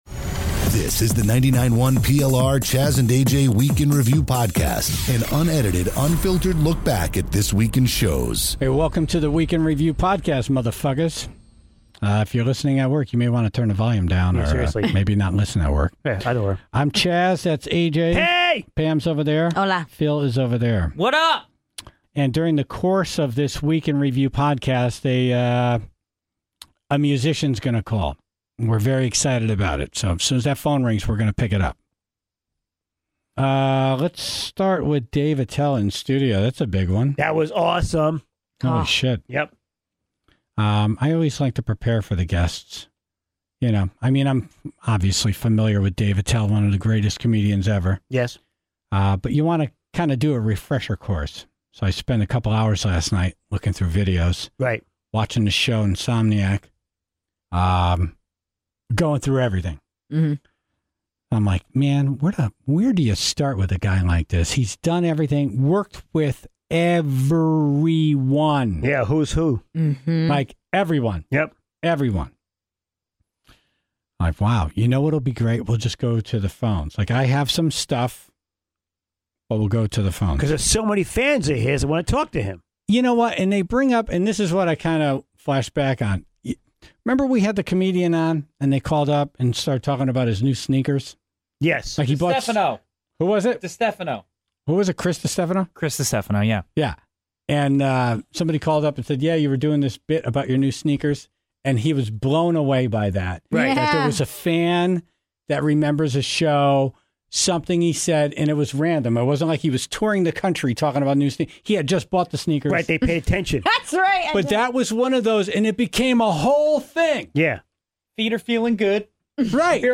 Everyone had a response to Dave Attell's appearance in studio this morning
were joined by a surprise guest on the phone